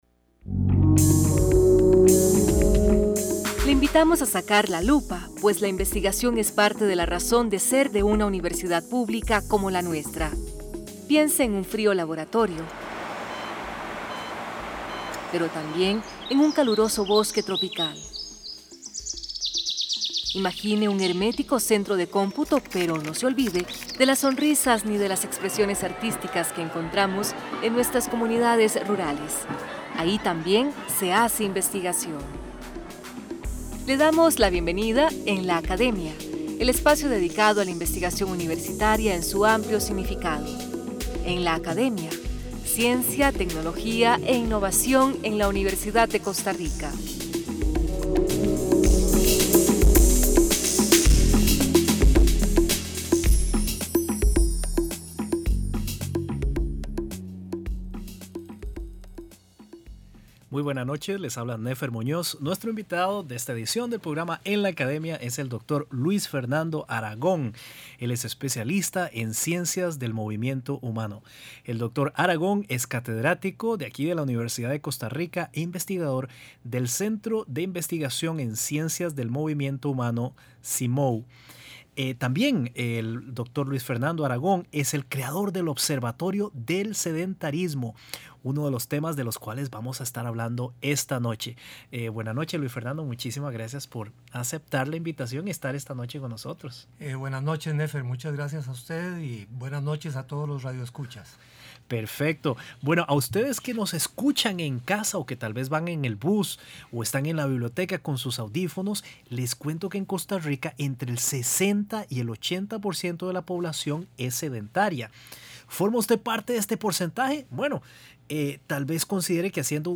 En esta entrevista radial se divulgan varios conceptos básicos y resultados alrededor del tema de sedentarismo. Se compara con la insuficiente actividad física y se conversa sobre los efectos nocivos de ambos sobre la salud.